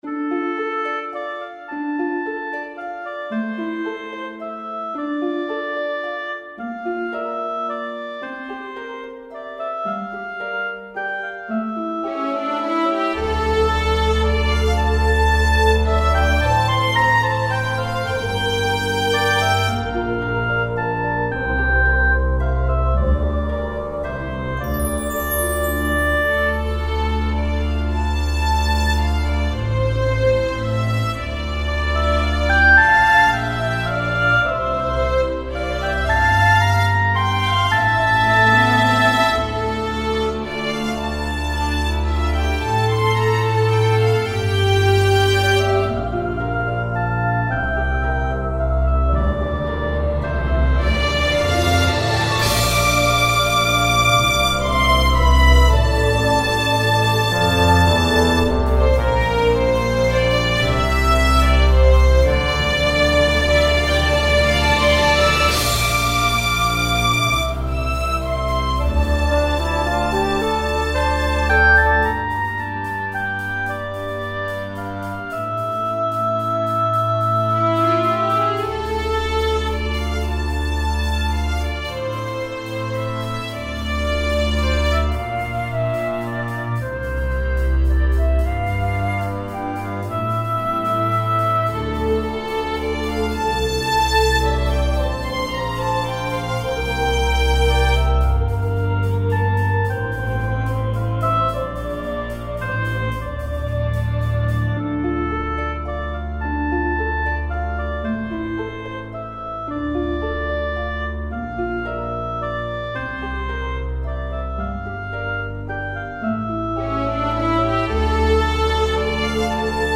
• ファンタジーRPGのフィールドBGM
静かで落ち着いた雰囲気なので、長時間の作業用BGMとしても使いやすい楽曲です。
主旋律には柔らかい木管系の音色を使用し温かく幻想的なメロディーを作りました。
テンポはゆったりめに設定し、自然の中で静かに流れる時間のような雰囲気を意識しています。
BGM ファンタジー 癒し 静かな